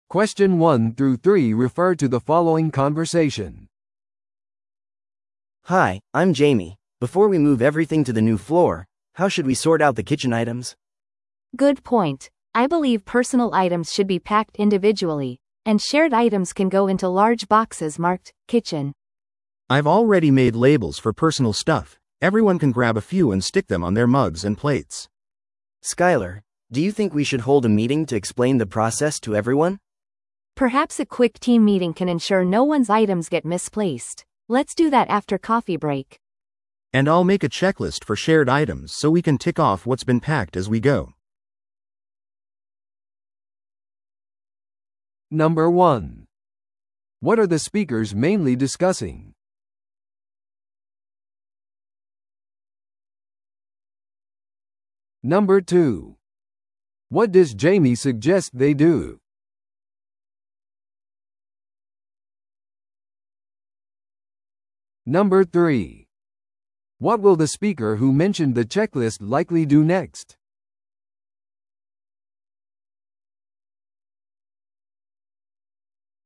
TOEICⓇ対策 Part 3｜新オフィスへのキッチン移動準備について – 音声付き No.103